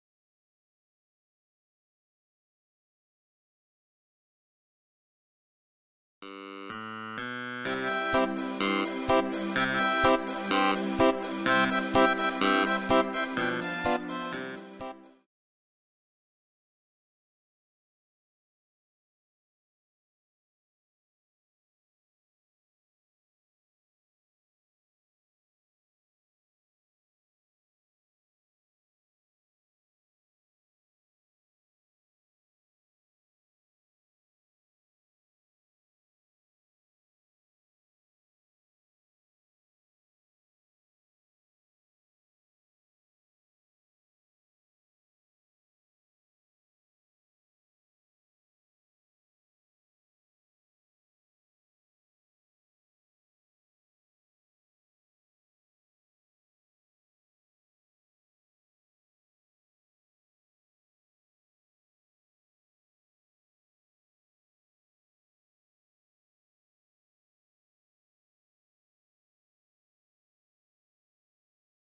Rubrika: Pop, rock, beat
Instrumentálka